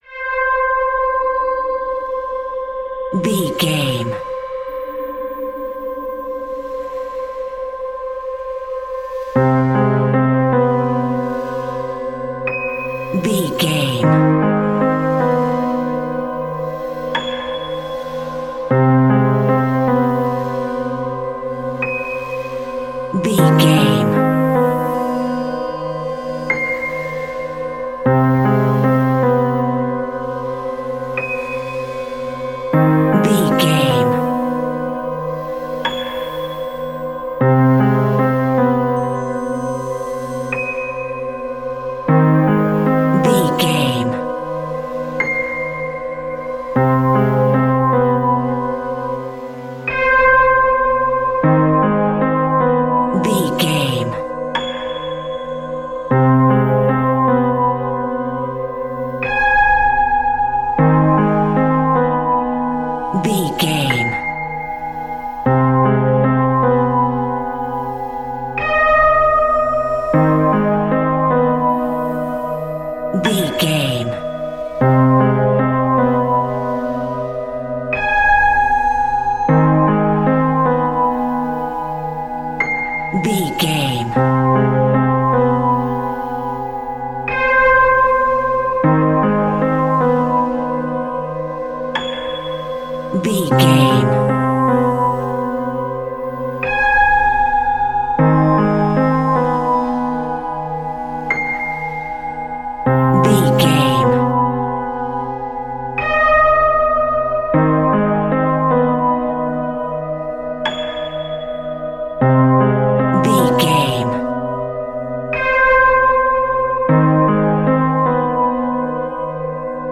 In-crescendo
Thriller
Aeolian/Minor
ominous
suspense
eerie
horror
Horror Synths
horror piano
Scary Strings